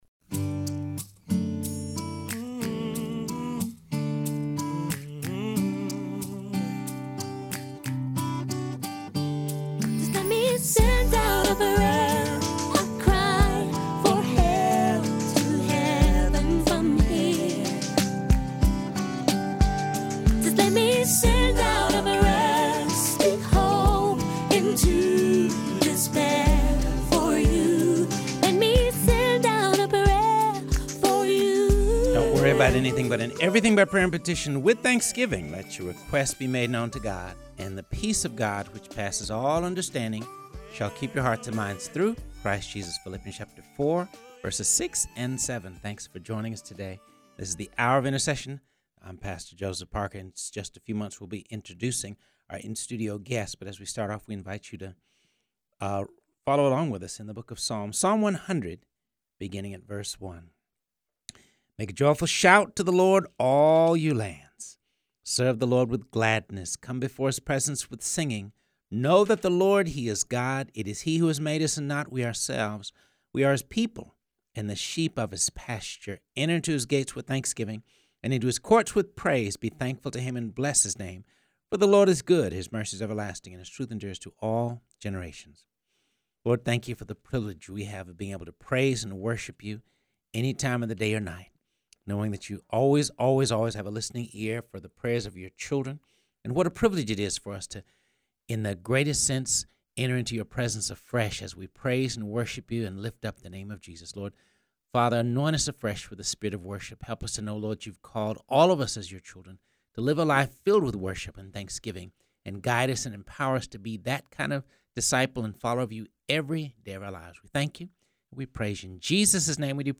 In-studio guests